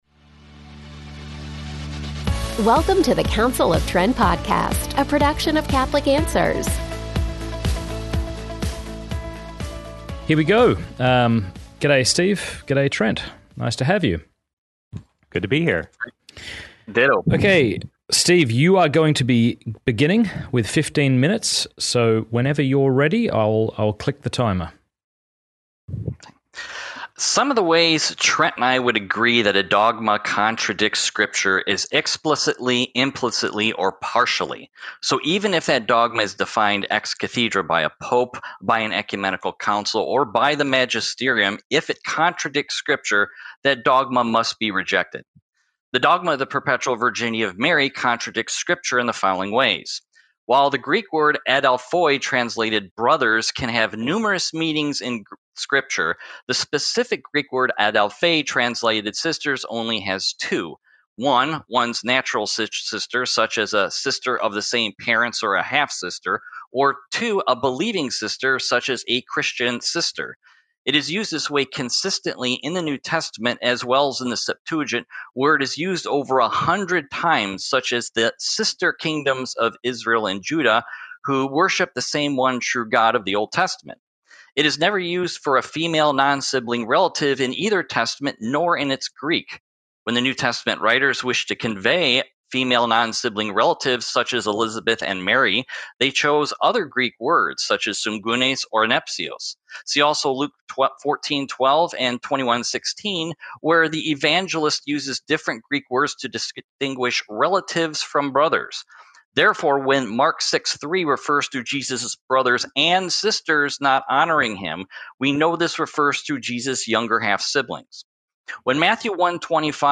DEBATE: Do the Marian Dogmas Contradict Scripture?
In this debate, originally hosted at Pints with Aquinas